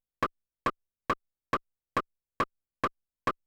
LO RIM    -L.wav